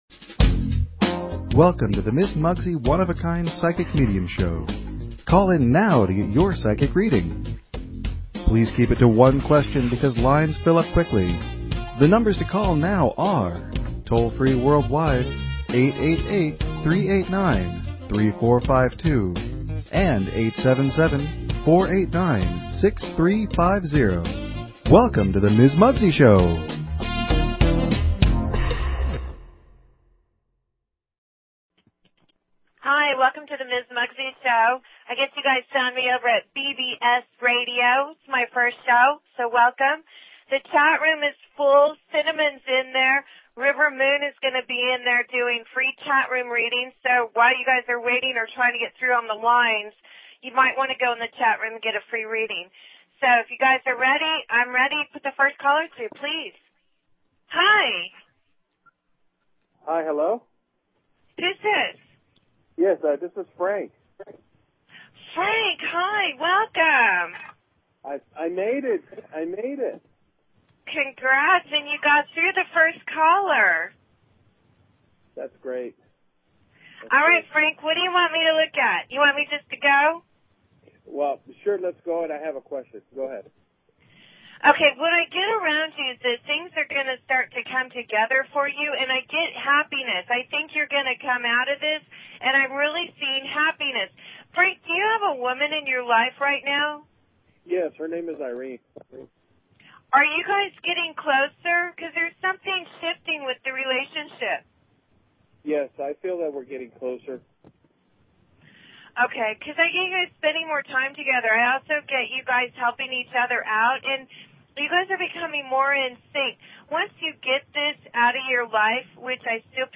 Talk Show Episode, Audio Podcast, One_of_a_Kind_Psychic_Medium and Courtesy of BBS Radio on , show guests , about , categorized as